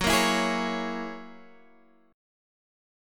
F#9 chord